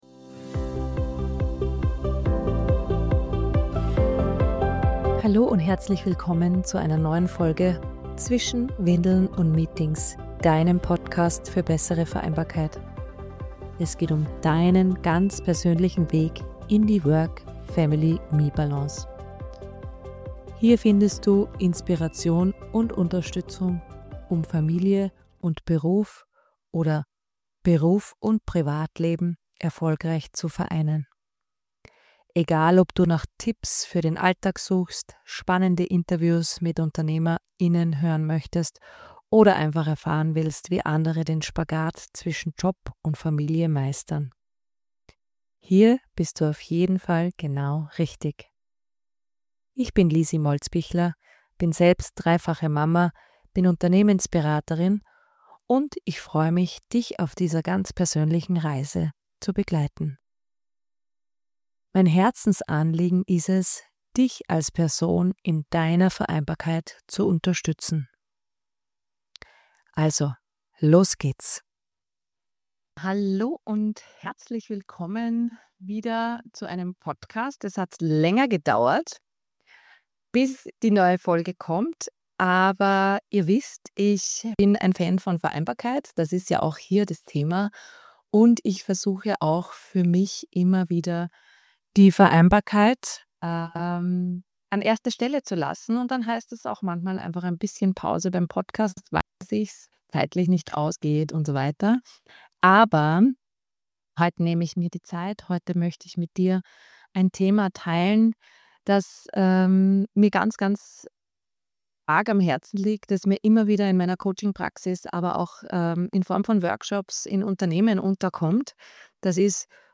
Folge #25: Work-Life-Blending statt Balance – wie du deinen Alltag neu denken darfst Viele von uns versuchen, Arbeit und Leben fein säuberlich zu trennen – doch was, wenn genau das der Stressfaktor ist? In dieser Solo-Folge spreche ich über:...